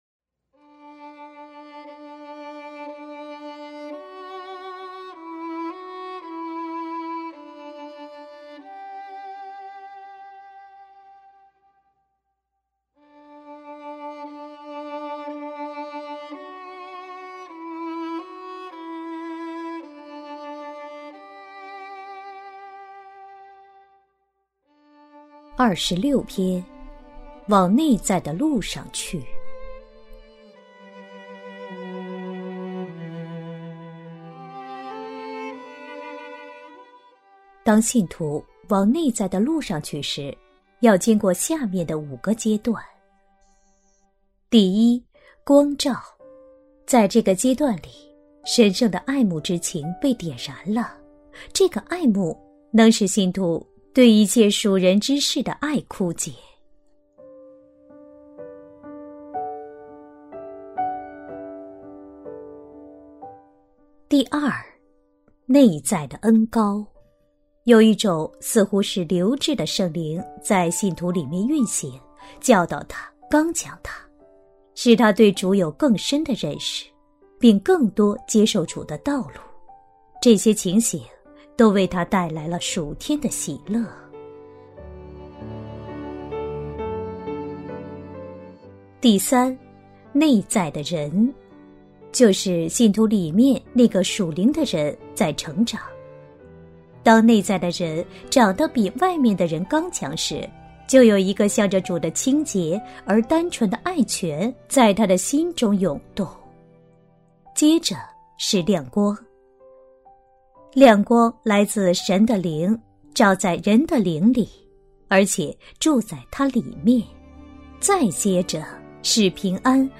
首页 > 有声书 | 灵性生活 | 灵程指引 > 灵程指引 第二十六篇 ：往内在的路上去